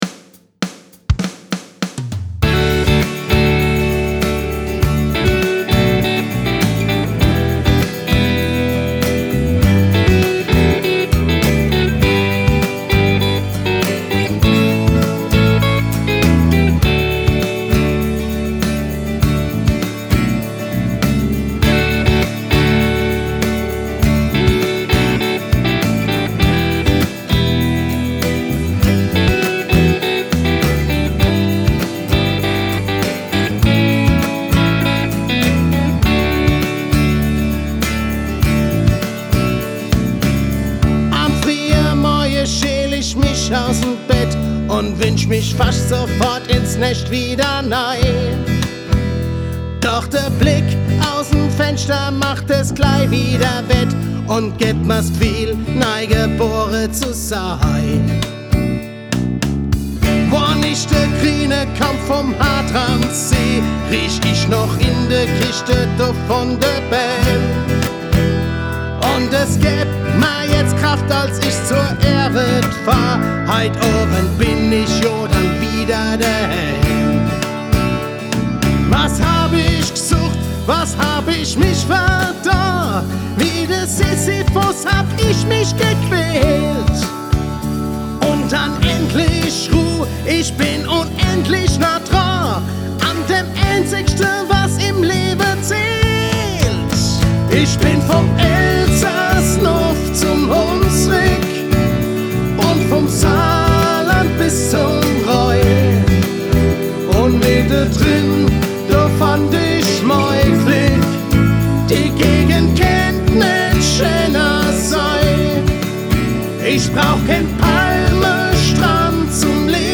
- 2023, Kategorie: Lied, 2. Platz -